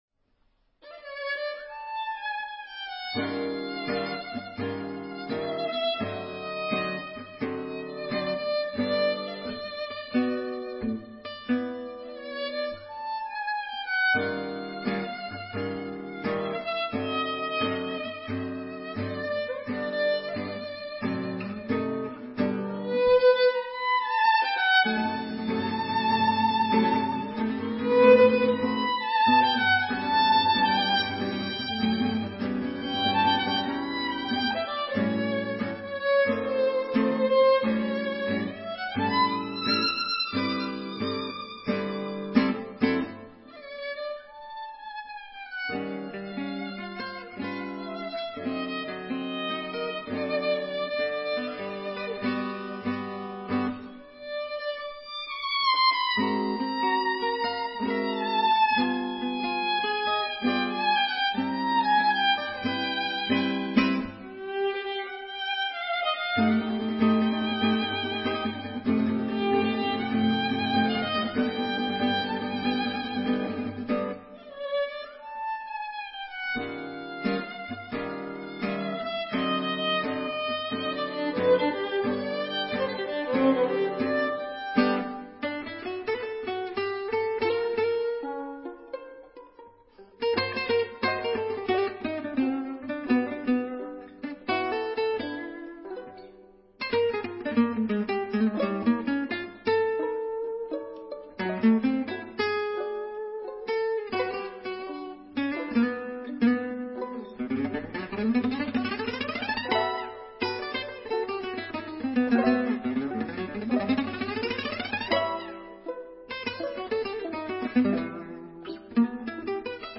violon